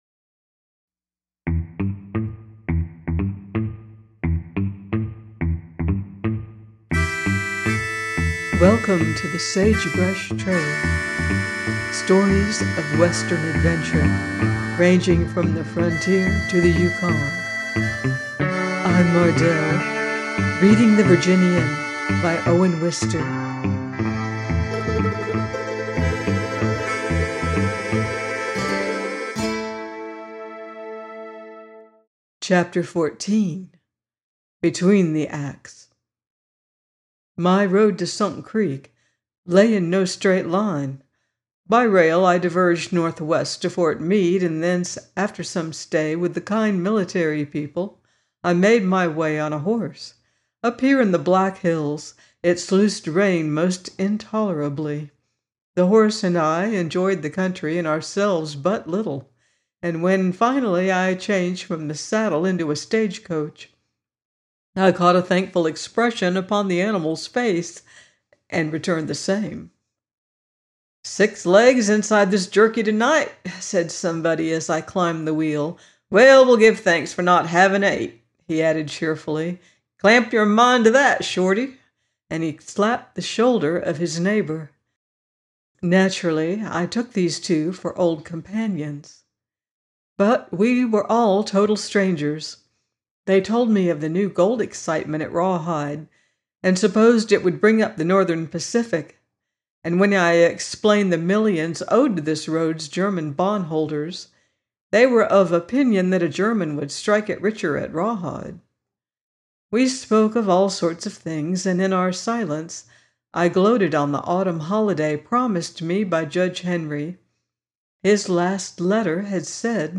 The Virginian 14 - by Owen Wister - audiobook